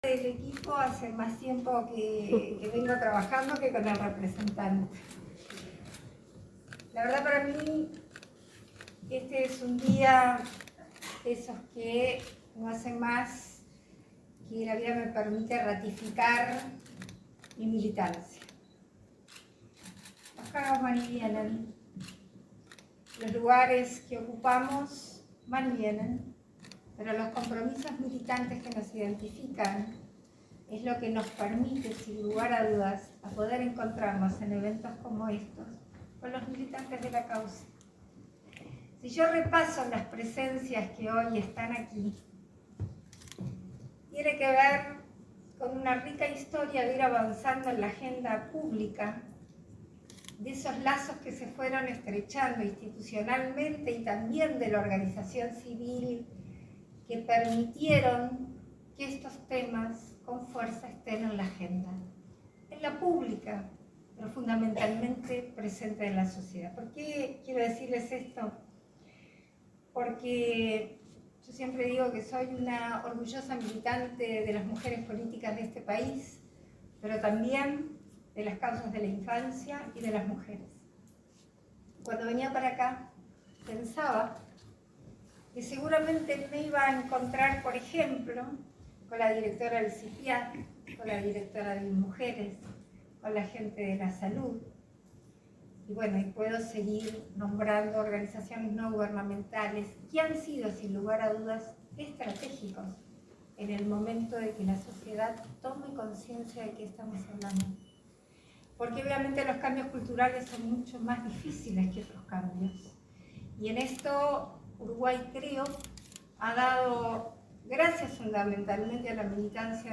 Palabras de la presidenta en ejercicio, Beatriz Argimón
Este miércoles 1:° en Montevideo, la presidenta en ejercicio, Beatriz Argimón, participó del lanzamiento de la campaña institucional de Unicef acerca